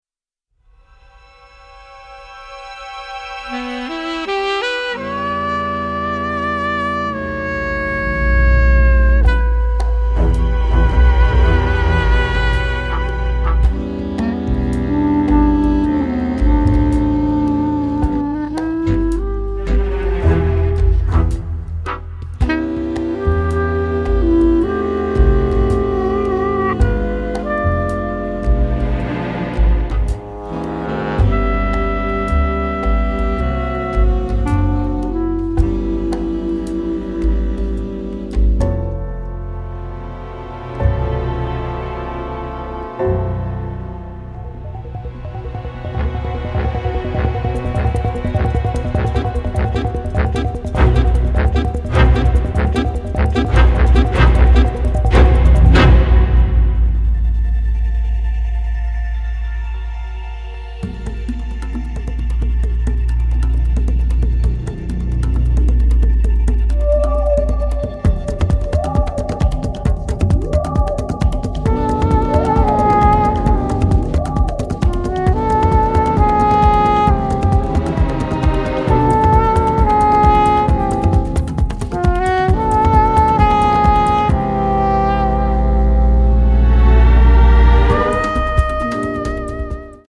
original score
elegant and contemporary lounge-style melodies